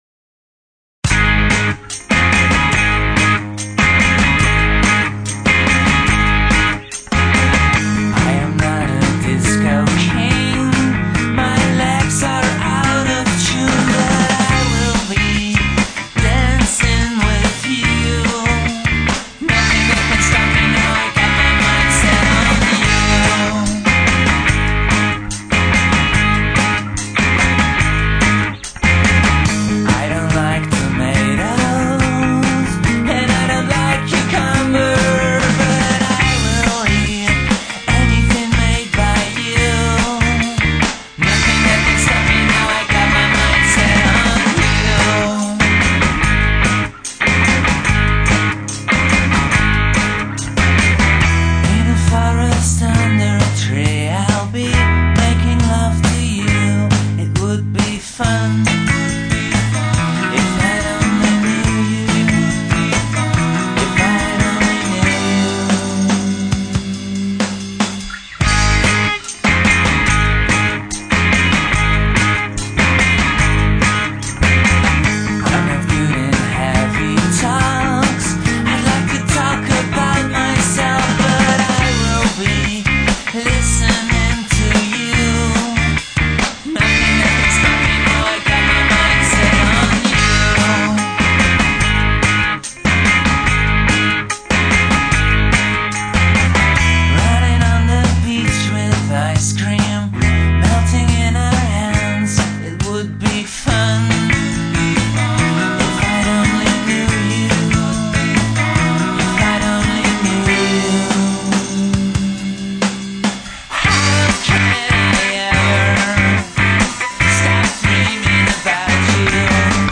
trivia: instant backing vocals made up in the studio